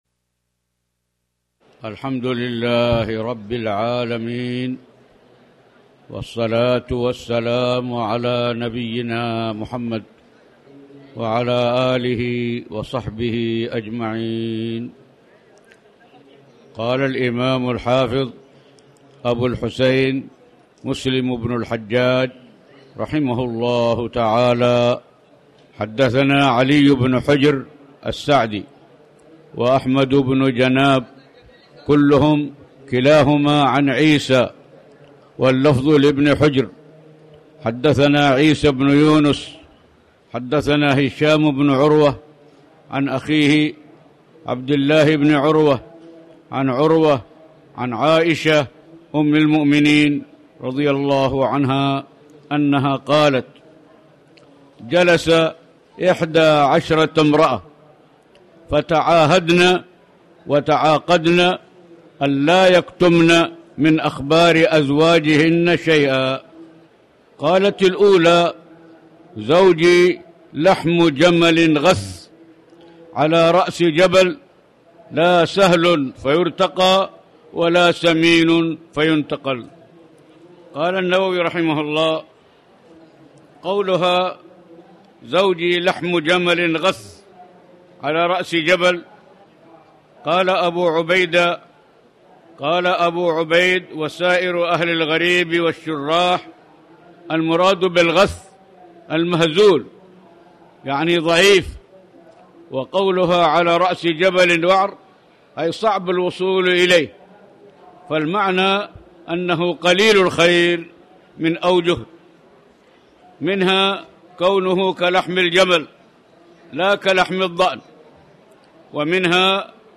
تاريخ النشر ٧ ربيع الثاني ١٤٣٩ هـ المكان: المسجد الحرام الشيخ